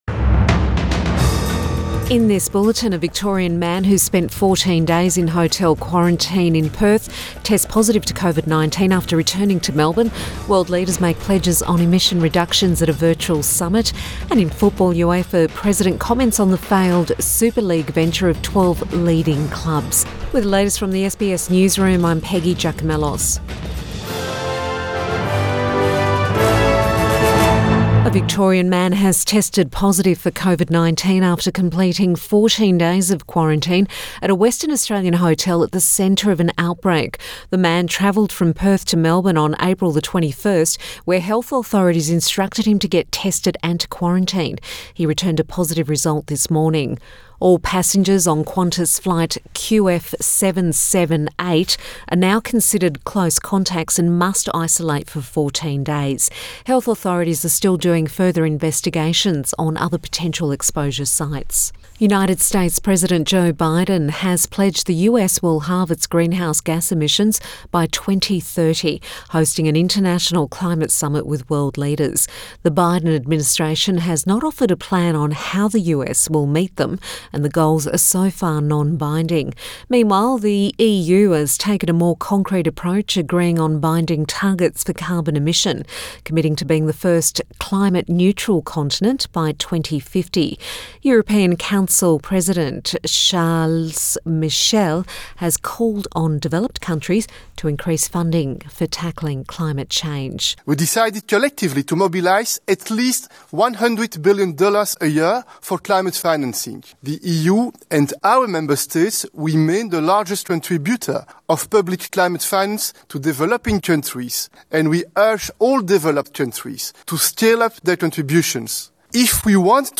Midday bulletin 23 April 2021